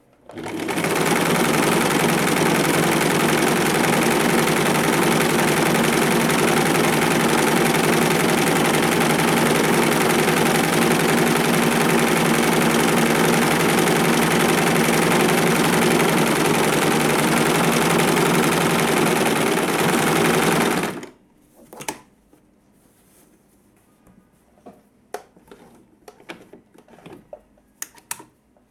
Máquina de coser 2
máquina de coser
Sonidos: Industria
Sonidos: Hogar